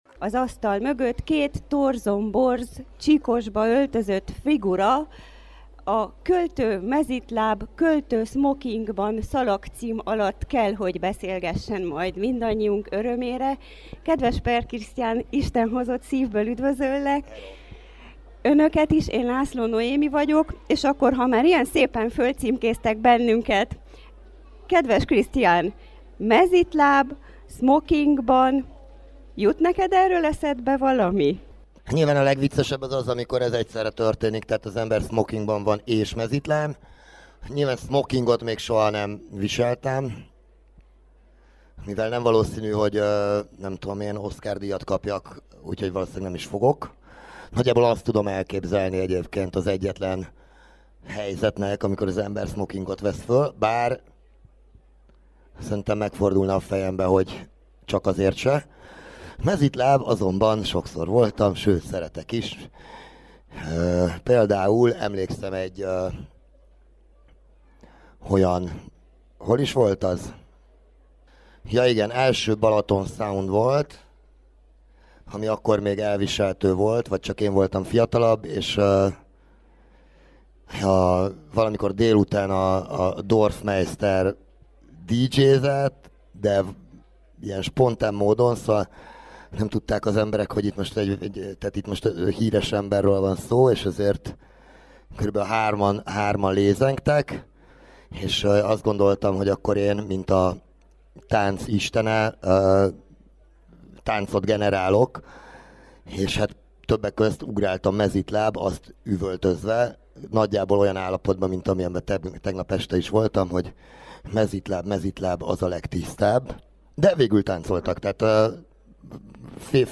Az Irodalmi Kávéházban Smokingban vagy mezítláb cím alatt